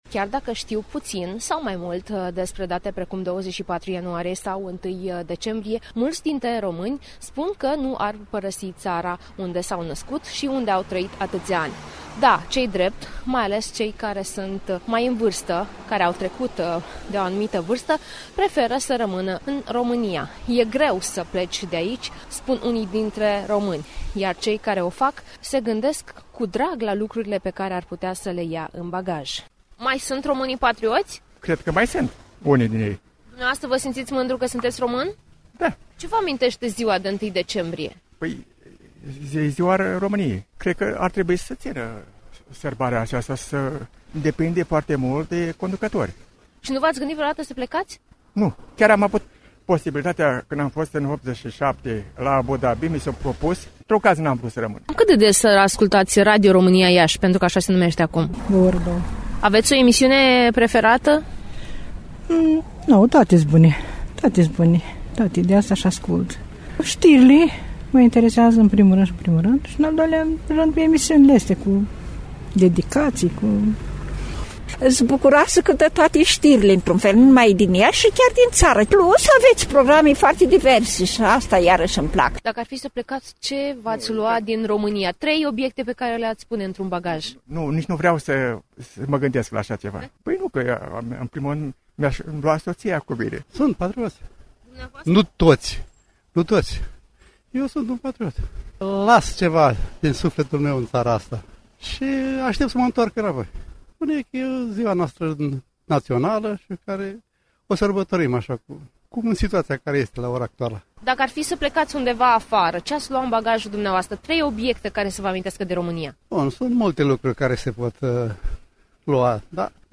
Manifestările dedicate zilei de 1 decembrie continuă la Iași.